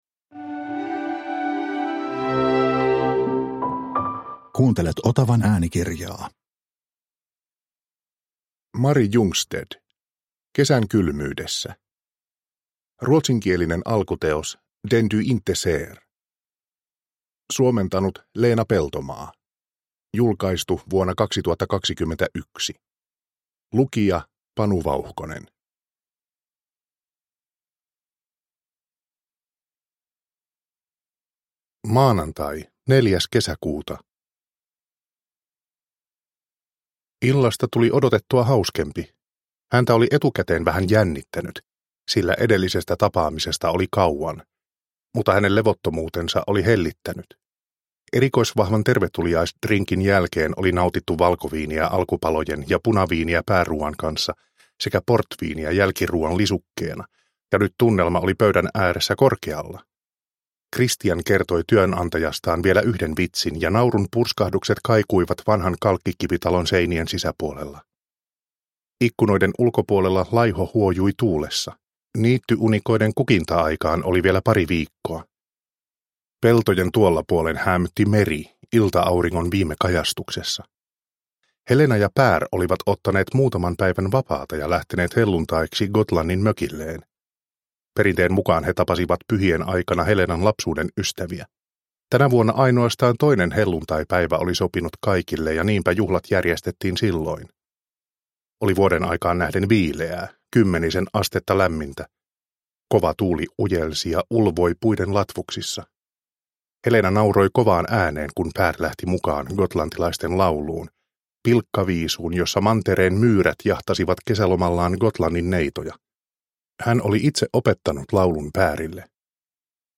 Kesän kylmyydessä – Ljudbok – Laddas ner